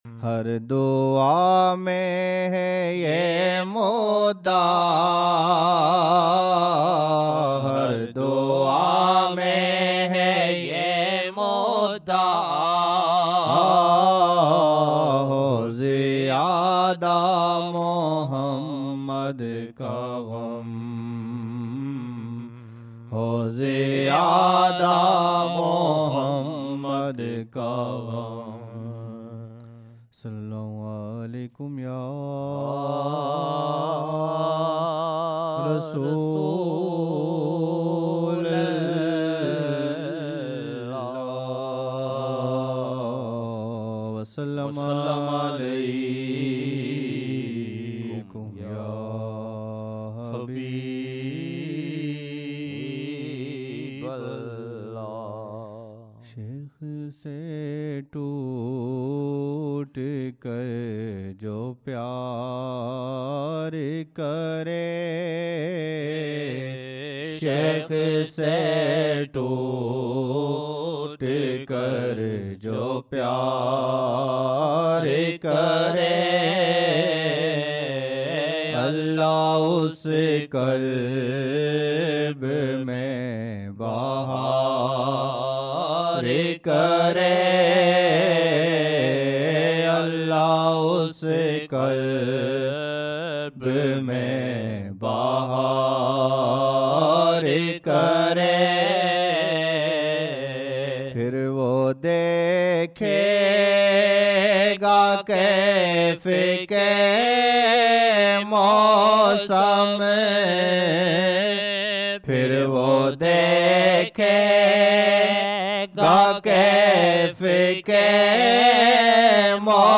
2 January 2006 Monday Esha Mehfil (2 Dhu al-Hijjah 1426 AH)